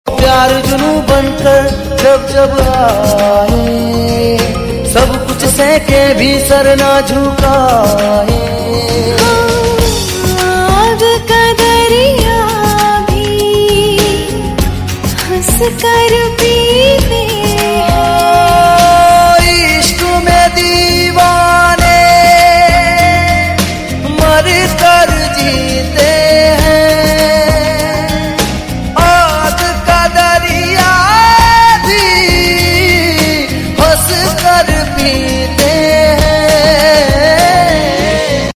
TV Serial Tone